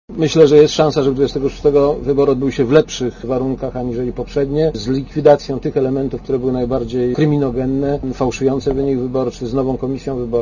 Komentarz audio Rozmowy mają doprowadzić do wyprowadzenia Ukrainy z głębokiego kryzysu, w jakim znalazło się to państwo po sfałszowaniu drugiej tury wyborów prezydenckich, 21 listopada.